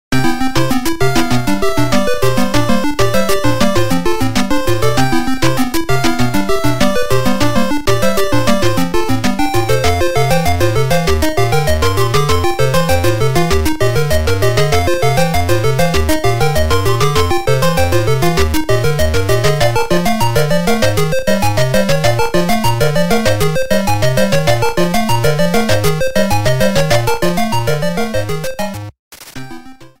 Self-recorded
Fair use music sample